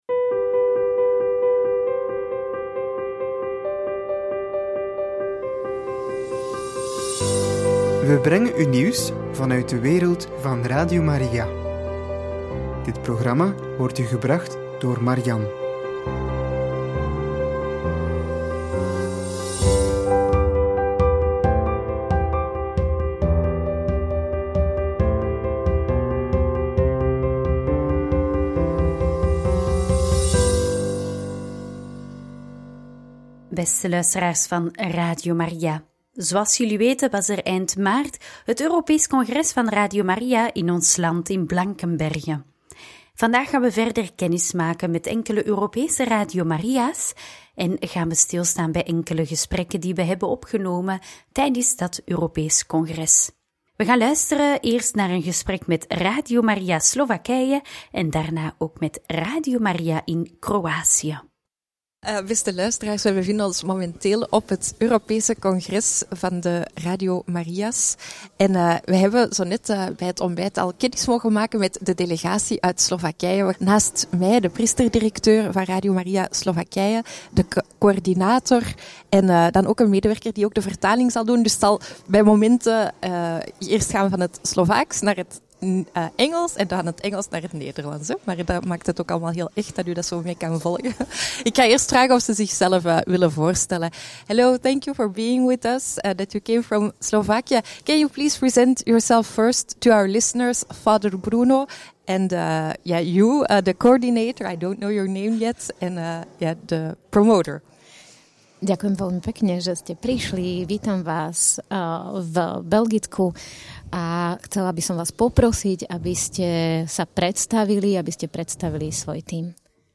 In gesprek met de delegatie van Radio Maria Slovakije en Radio Maria Kroatië – Radio Maria
in-gesprek-met-de-delegatie-van-radio-maria-slovakije-en-radio-maria-kroatie.mp3